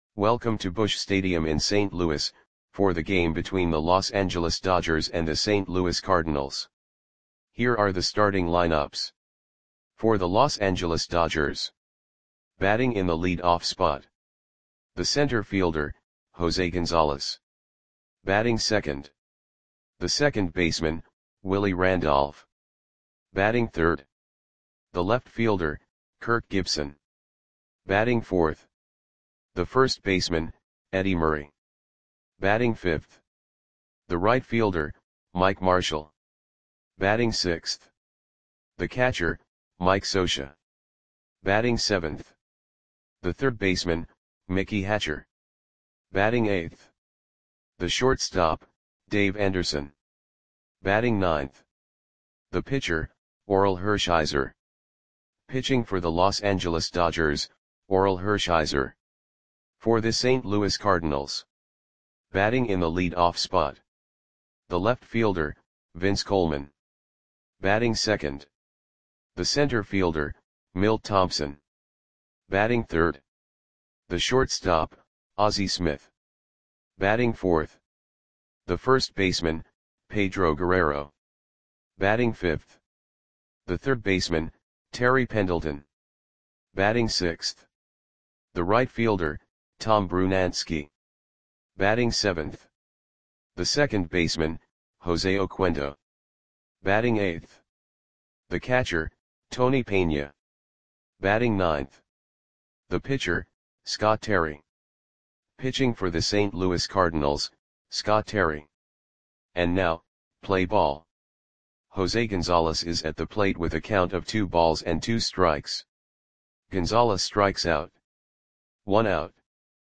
Lineups for the St. Louis Cardinals versus Los Angeles Dodgers baseball game on July 5, 1989 at Busch Stadium (St. Louis, MO).
Click the button below to listen to the audio play-by-play.